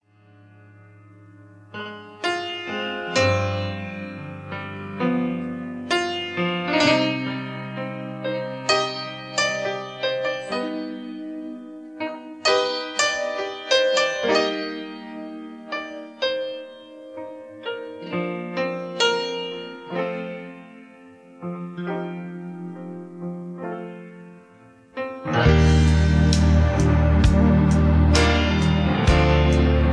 Key-Bb) Karaoke MP3 Backing Tracks